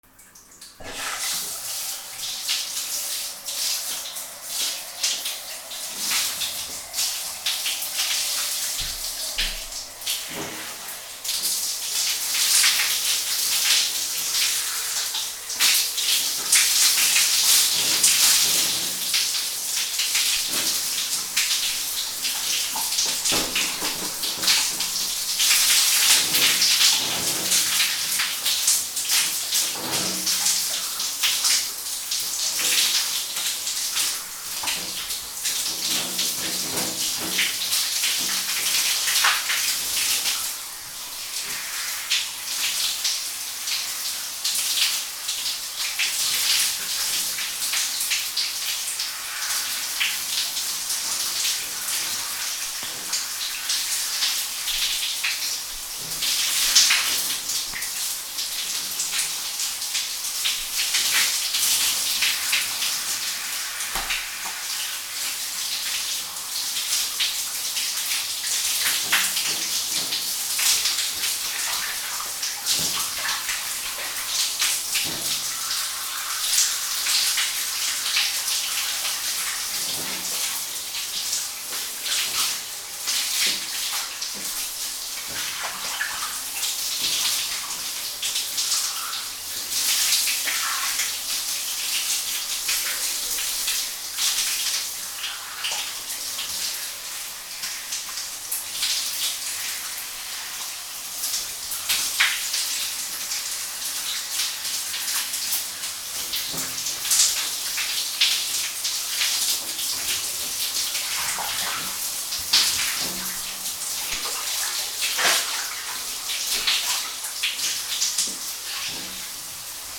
シャワーを浴びる
/ M｜他分類 / L05 ｜家具・収納・設備 / お風呂